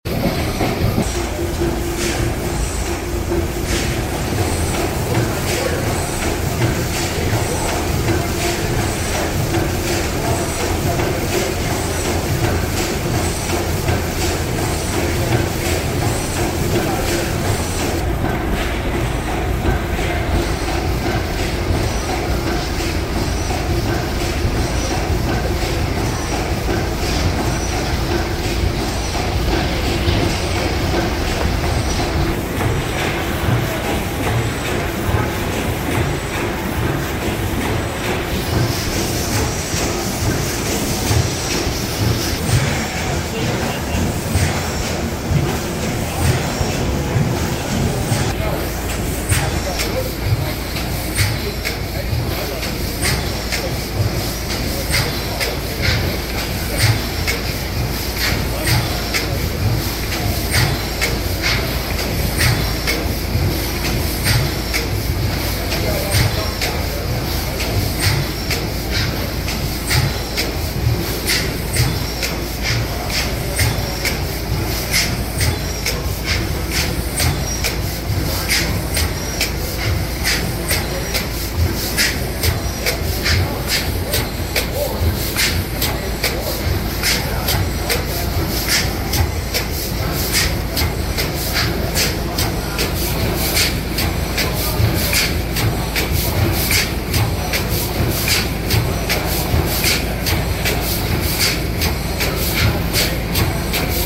600 Horsepower Snow Gas Engine sound effects free download
600 Horsepower Snow Gas Engine startup Part 3